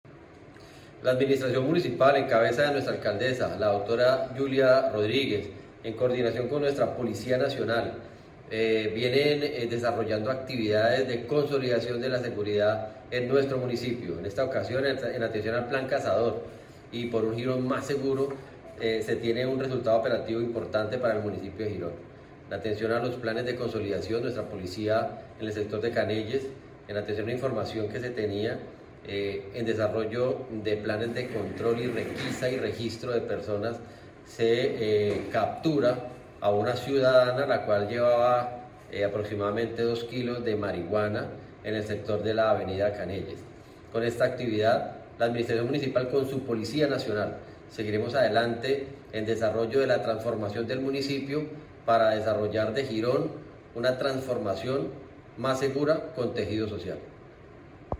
Secretario de Seguridad, Juan Carlos Pinto.mp3